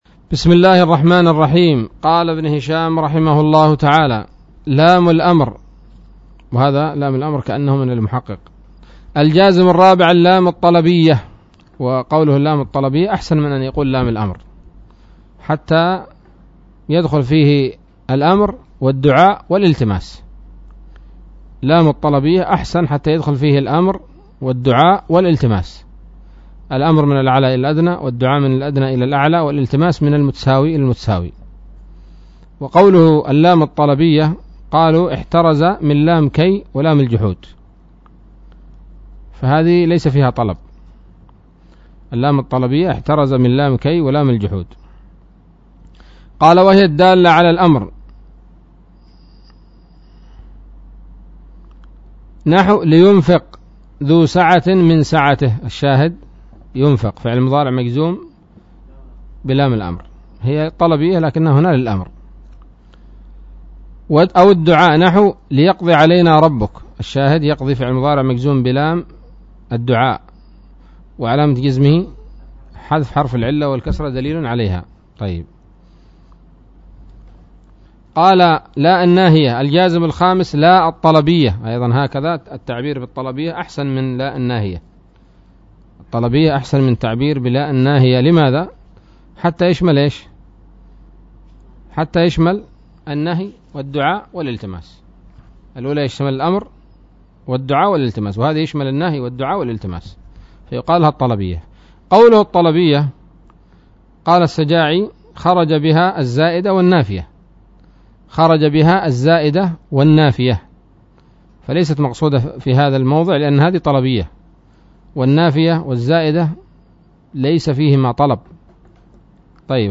الدرس الثامن والثلاثون من شرح قطر الندى وبل الصدى